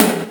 tom 1.wav